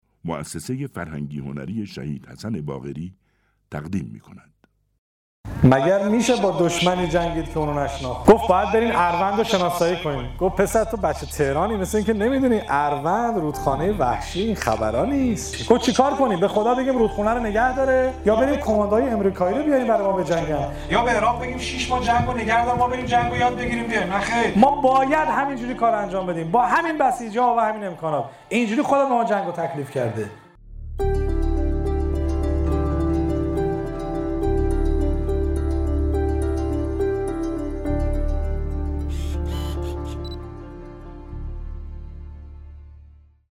روایتگری در یادمان شهید حسن باقری درباره تکالیف مردم در جنگ